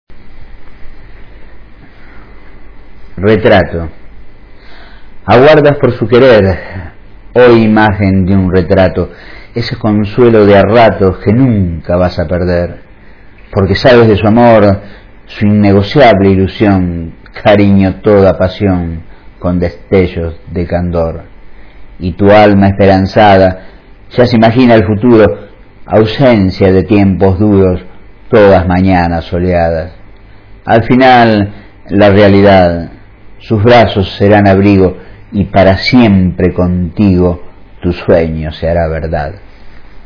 Recitado por el autor (0:39", 155 KB)